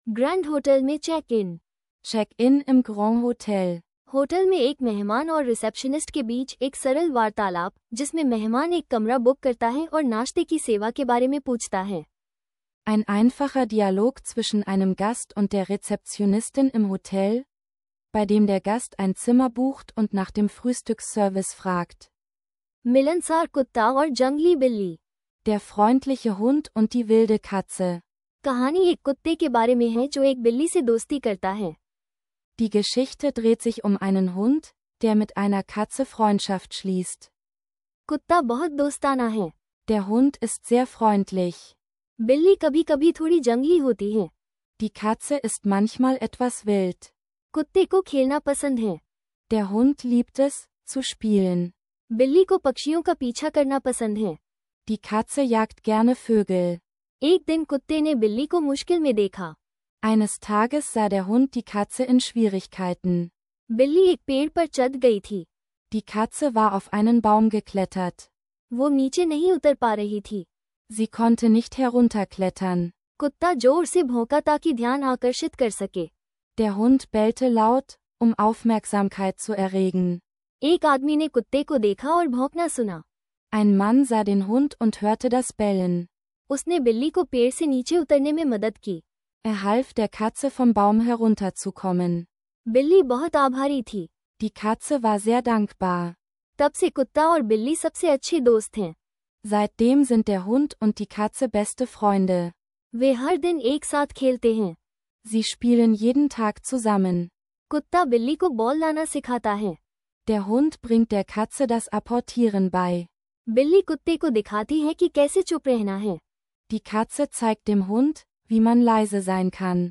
Erlebe praxisnahe Hindi-Dialoge – Perfekt zum Lernen unterwegs und für Anfänger!